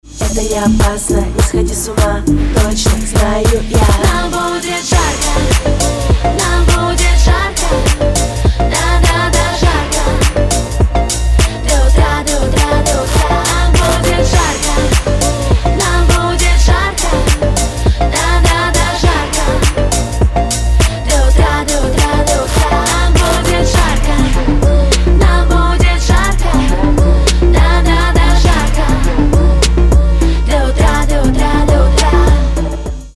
• Качество: 320, Stereo
поп
dance
RnB
vocal